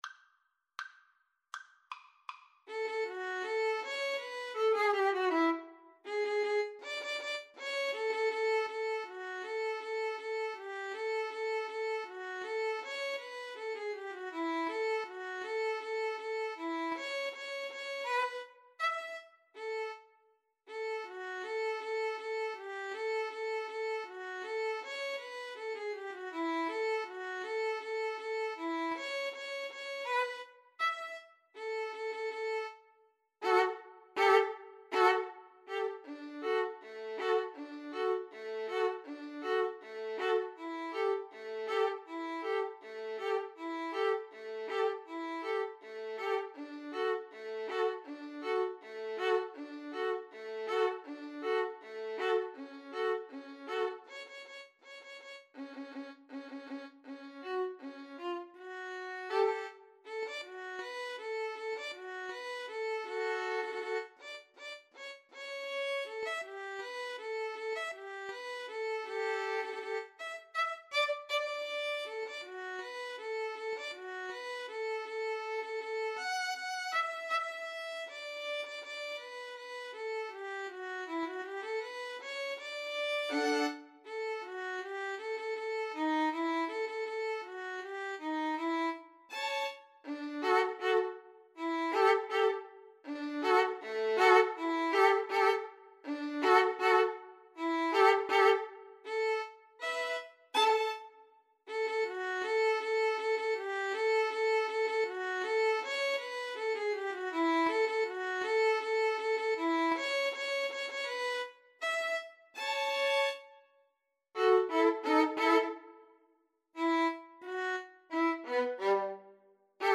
"The Parade of the Tin Soldiers" (Die Parade der Zinnsoldaten), also known as "The Parade of the Wooden Soldiers", is an instrumental musical character piece, in the form of a popular jaunty march, written by German composer Leon Jessel, in 1897.
D major (Sounding Pitch) (View more D major Music for Violin-Cello Duet )
Not Fast =80
Violin-Cello Duet  (View more Intermediate Violin-Cello Duet Music)
Classical (View more Classical Violin-Cello Duet Music)